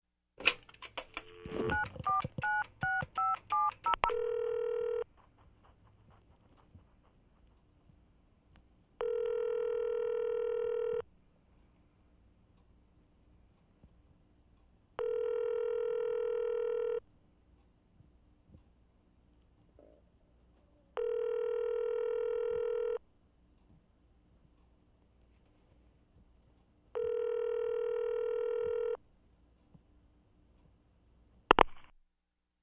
На этой странице собраны звуки набора номера в телефоне — от винтажных импульсных гудков до современных тональных сигналов.
Звук снятия трубки, набор номера на кнопочном телефоне, продолжительные гудки